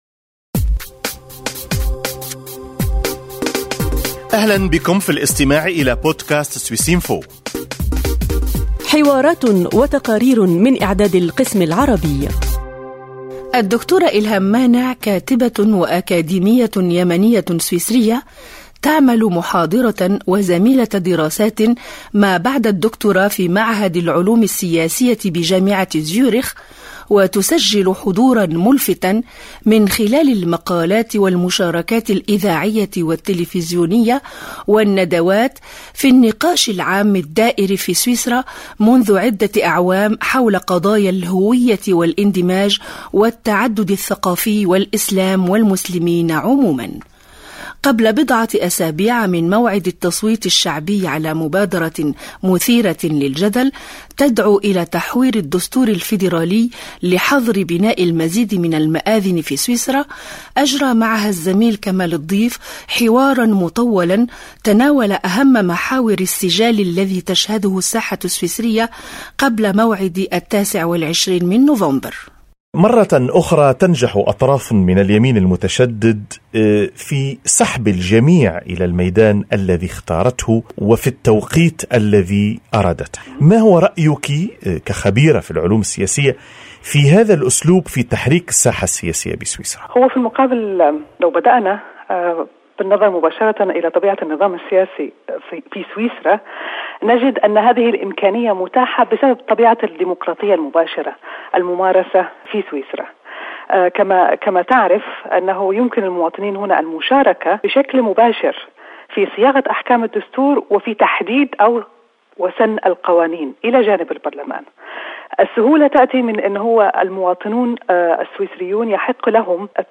حديث